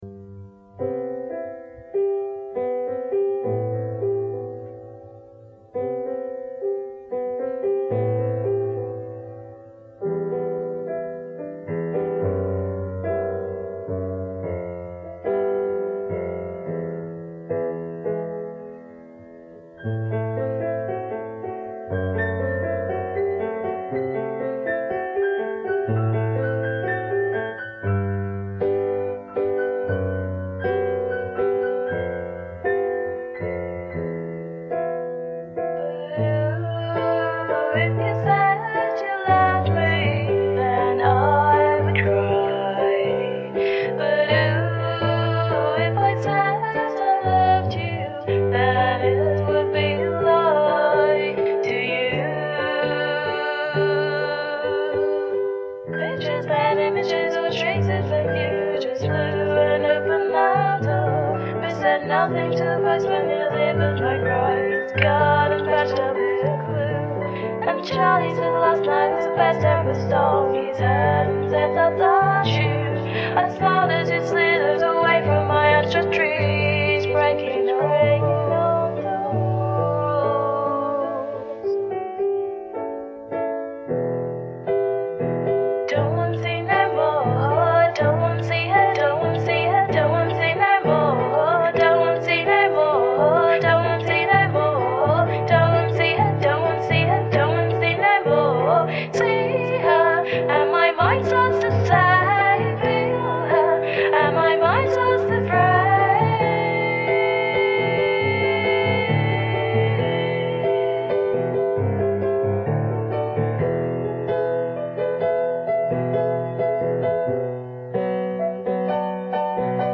There were 2 each of the piano and vocal parts